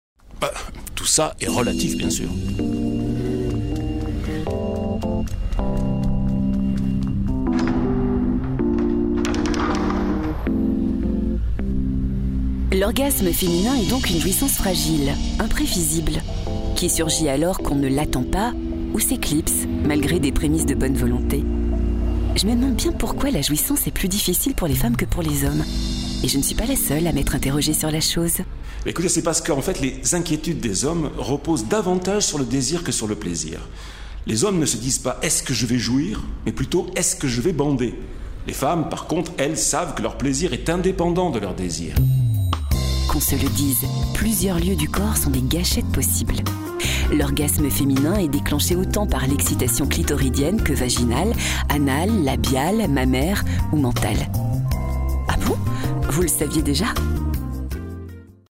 sehr variabel
Mittel minus (25-45)
Narrative